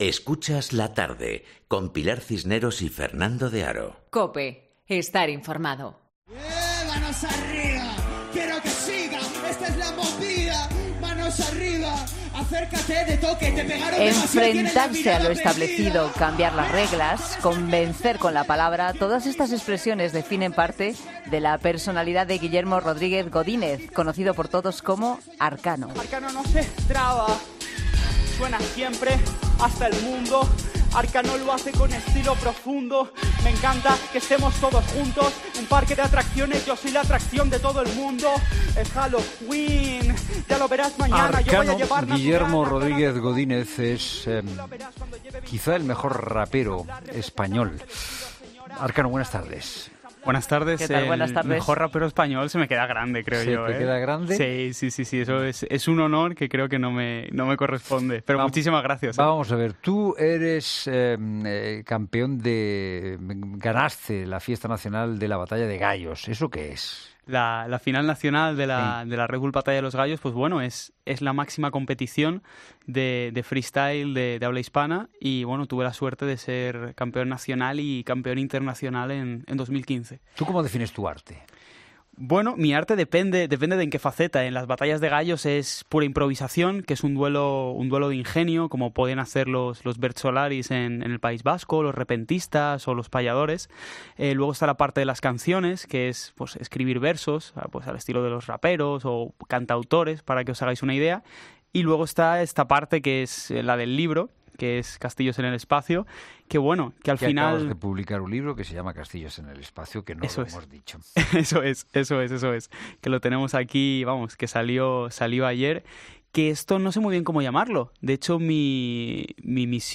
ESCUCHA LA ENTREVISTA COMPLETA EN 'LA TARDE' En una sociedad que se mueve sin perder de vista a los influencers, de lo que aparece en Instagram, de algunos humoristas, críticos, políticos, periodistas…